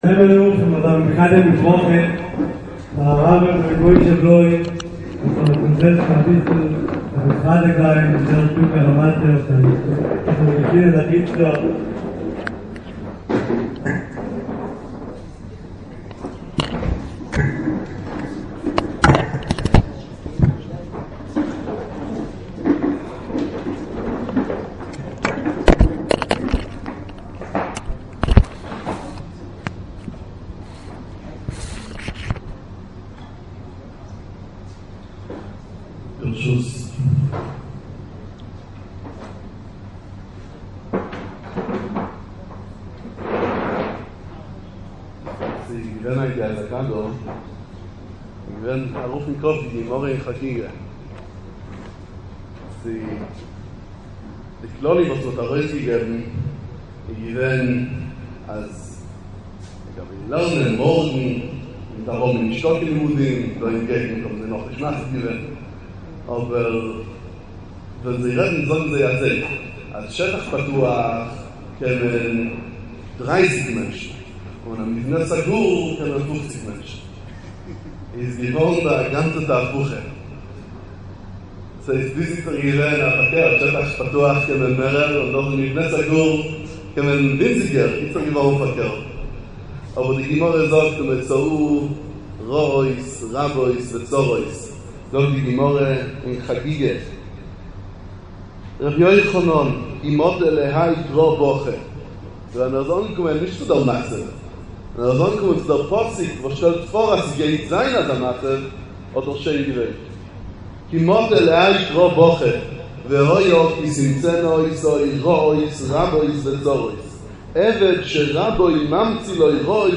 דברי חיזוק בעצרת התעוררות ככלות השבעה להרוגי אסון מירון תשפ"א
דרשת התעוררות